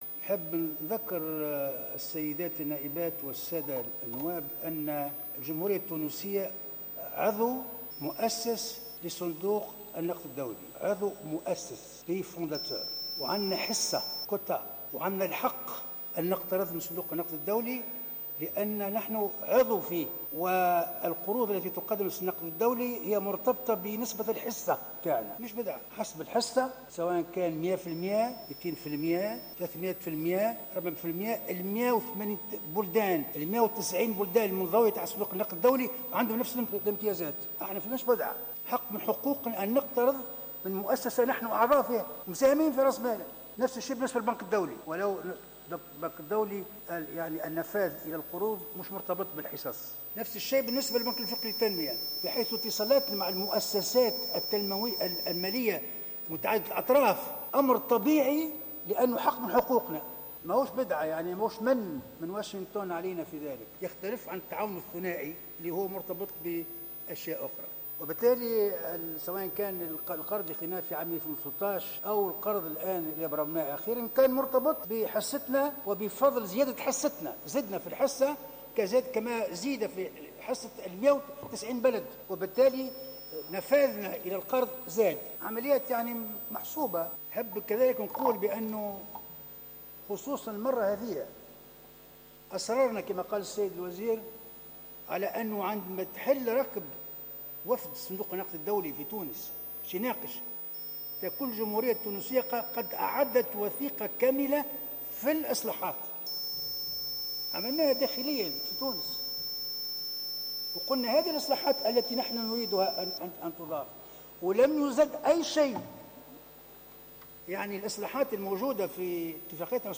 قال محافظ البنك المركزي التونسي الشاذلي العياري إن تونس متأخرة بعشر سنوات فيما يتعلق بالإصلاحات المالية مقارنة بالدول التي شرعت في هذه الإصلاحات مثل الأردن والمغرب، بحسب ما جاء في نقل مباشر لجلسة عامة لمجلس نواب الشعب اليوم الثلاثاء.
وجاءت كلمة محافظ البنك المركزي للردّ على أسئلة النواب حول مشروع قانون متعلق بالبنوك والمؤسسات المالية.